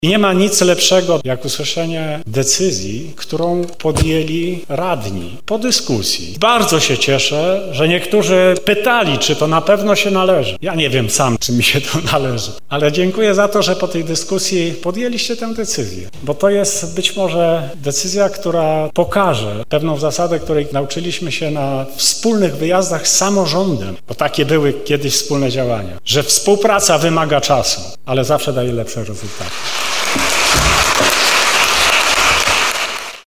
11 listopada były prezydent Nowego Sącza Andrzej Czerwiński został wyróżniony tytułem honorowego obywatela miasta. Odznaczenie przekazano podczas uroczystej sesji Rady Miasta Nowego Sącza.